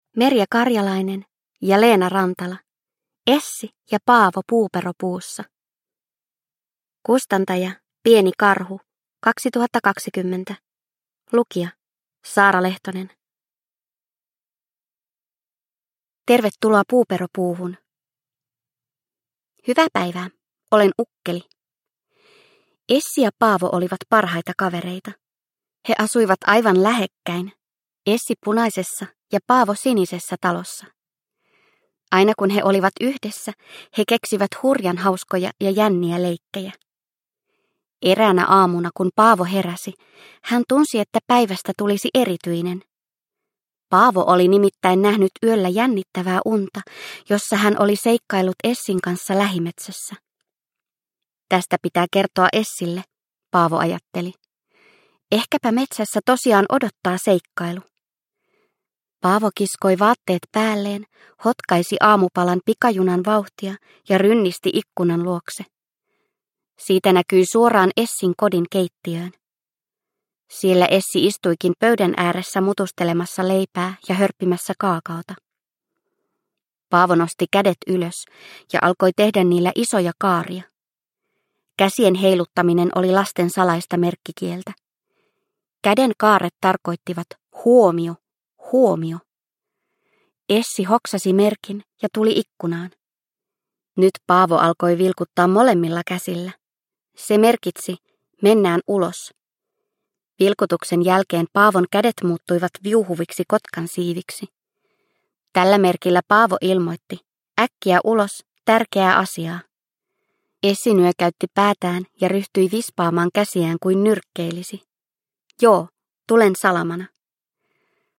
Essi ja Paavo Puuperopuussa – Ljudbok – Laddas ner